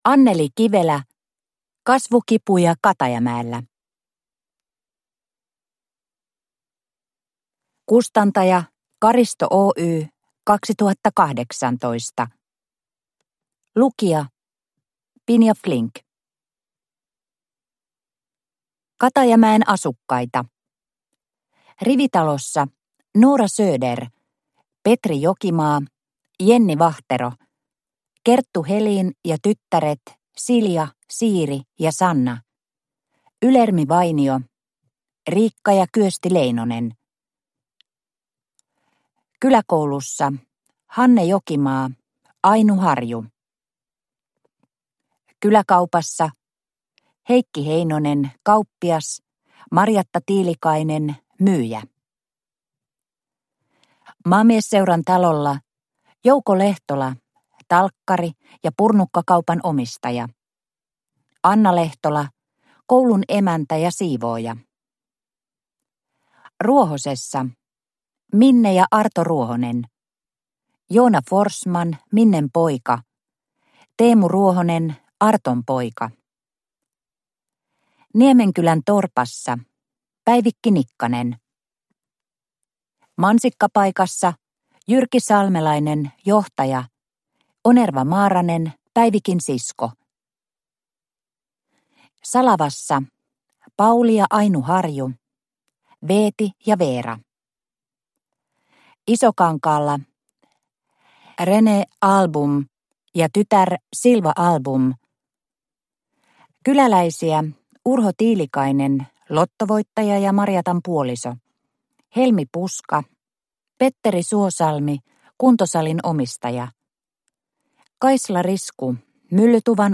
Kasvukipuja Katajamäellä – Ljudbok – Laddas ner